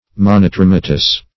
Search Result for " monotrematous" : The Collaborative International Dictionary of English v.0.48: Monotrematous \Mon`o*trem"a*tous\, a. (Zool.)
monotrematous.mp3